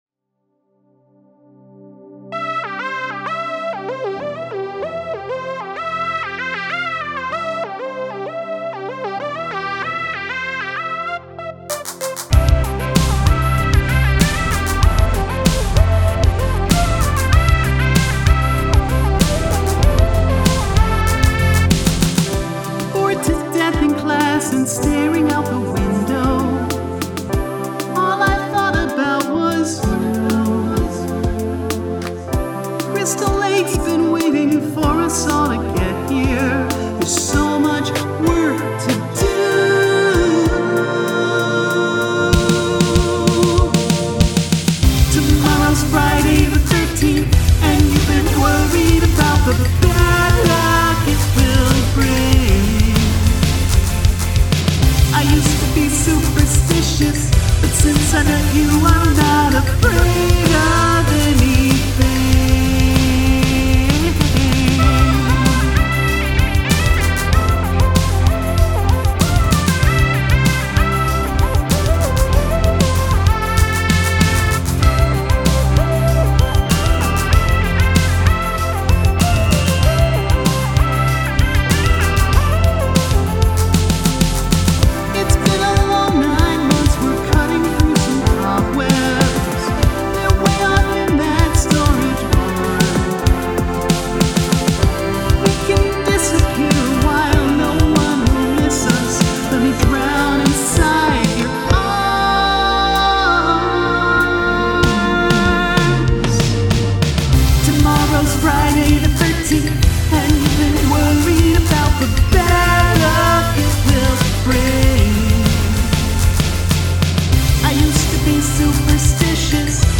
write a feel-good song with happy lyrics and upbeat music
pretty catchy with an energetic vocal performance, I don't have much to say about this one - the mix feels like it could be a bit cleaner maybe to really lean into the pop vibes, but overall it's well done.
It's a little too 80s for my taste but there's some great hooks and lyrical crafting in there.
All the twinkly little synth bits in the background are fantastic.